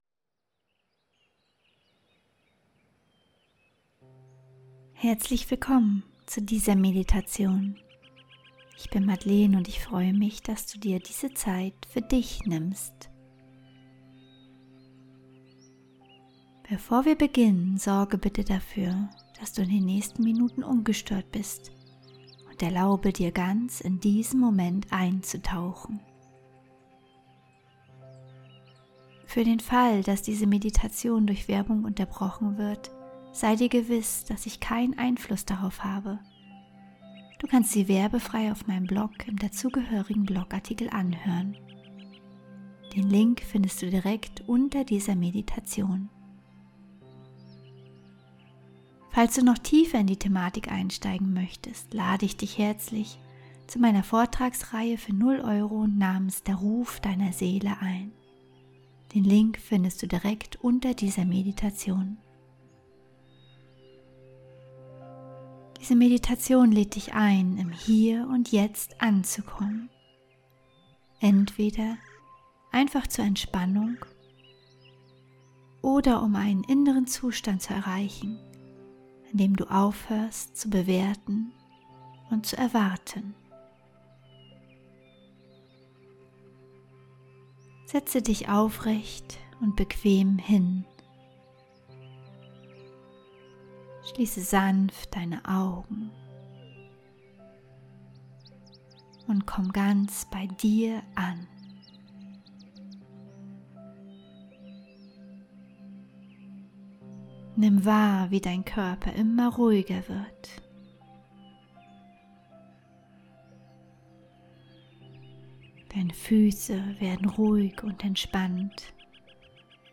Willkommen zu dieser 13-minütigen geführten Meditation – speziell gestaltet für alle, die noch am Anfang ihrer Meditationsreise stehen. In dieser Meditation lernst du, deine Gedanken loszulassen, ohne sie zu bekämpfen. Du kommst im Hier und Jetzt an – ganz sanft, ganz in deinem eigenen Tempo.
13_Min_gefuehrte_Meditation_fuer_Anfaenger_Von_Gedankenflut_zur_inneren_Stille.mp3